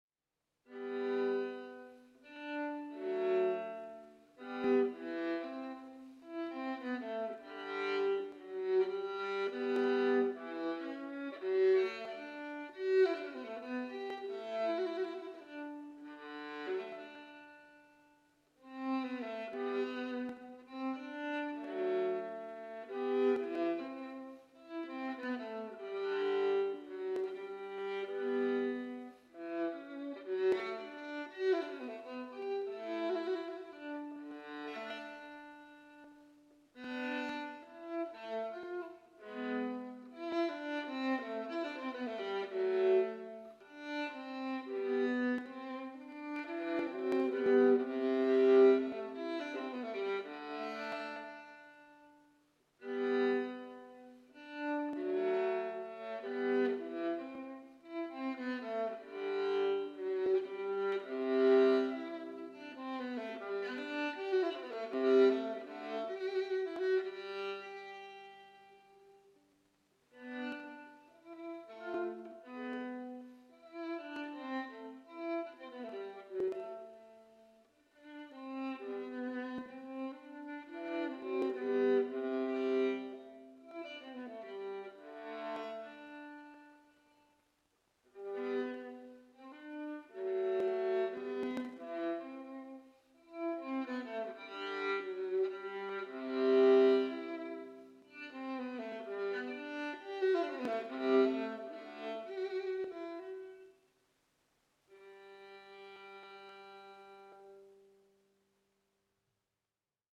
And then at about 3 this morning, very quietly, so as not to wake anybody, I played the Adagio from his G Major Gamba Sonata. There are lots of clicks and pops, as this was a very ad hoc session, as close as possible to the mic on the desk.